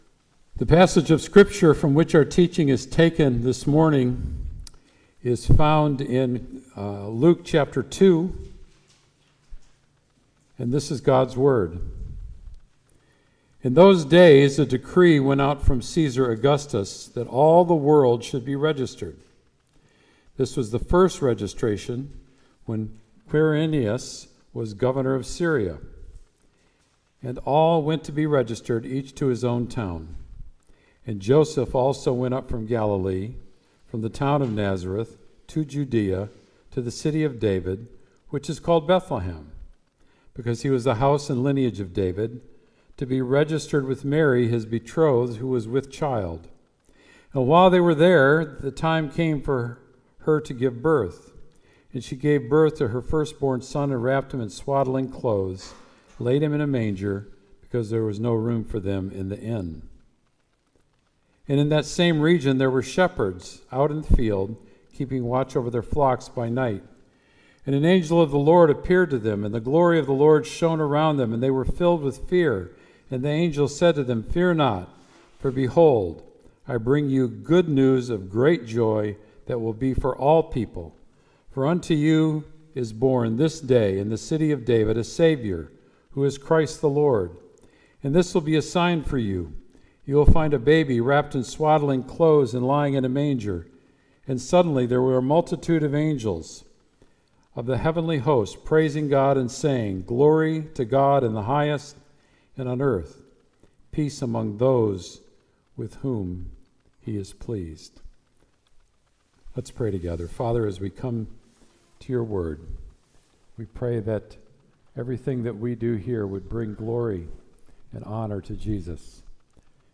Sermon “A Saviour